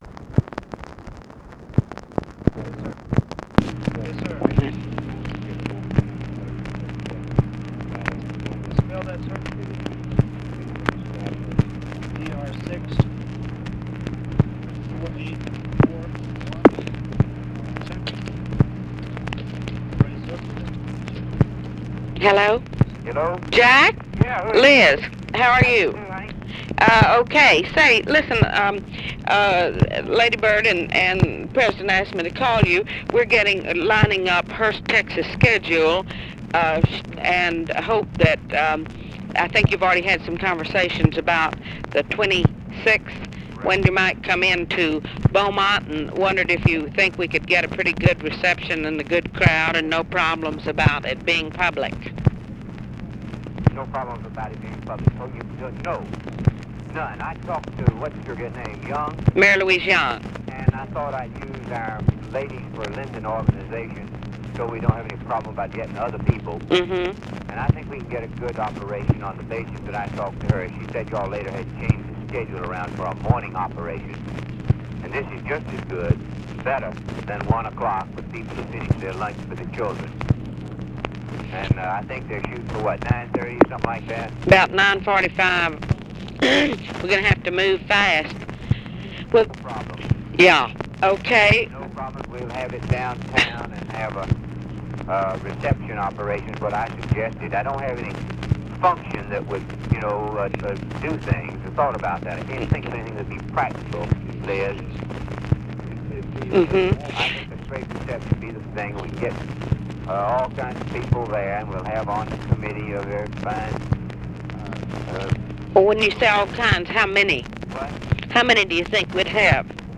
Conversation with JACK BROOKS, LIZ CARPENTER and UNIDENTIFIED MALE, October 11, 1964
Secret White House Tapes